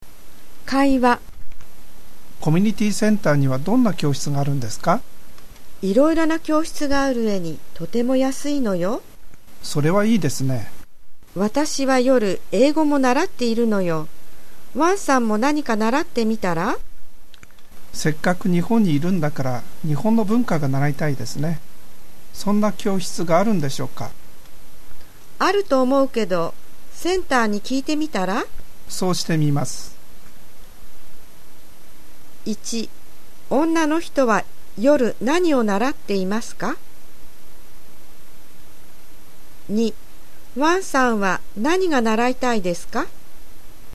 【会話】(conversation)